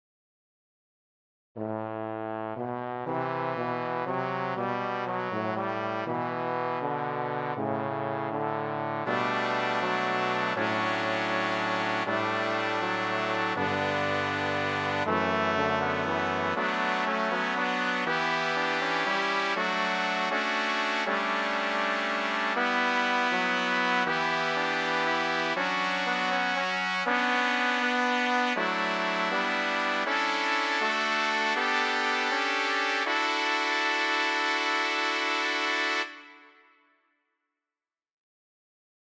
Key written in: D Major
How many parts: 4
Type: Barbershop
All Parts mix:
Learning tracks sung by